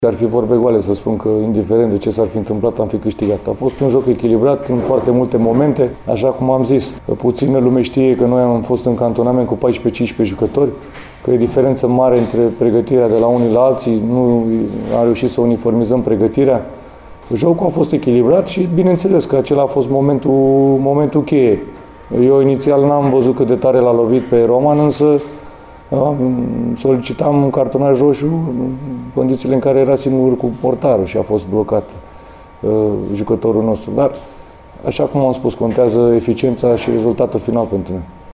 Tehnicianul gorjenilor, Eduard Iordănescu, a precizat şi el, după meci, că faza eliminării a reprezentat momentul cheie al meciului.